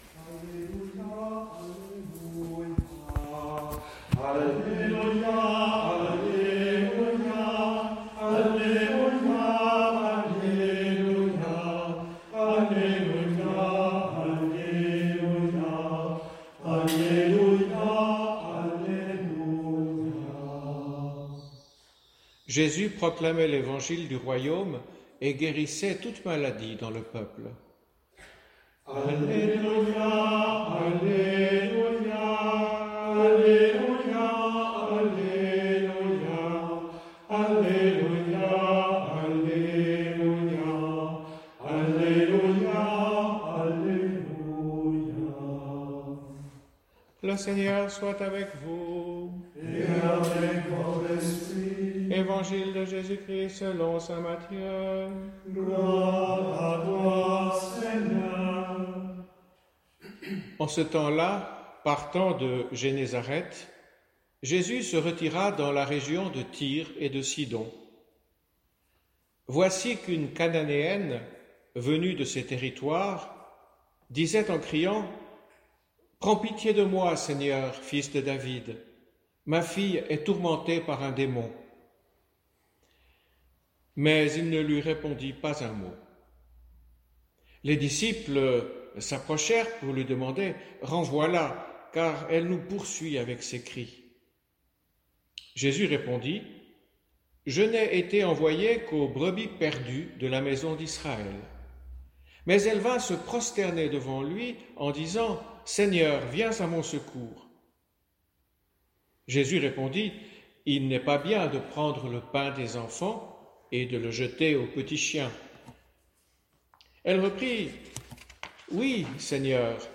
Enregistrement en direct
Pour ce dimanche de la 20ème semaine du Temps Ordinaire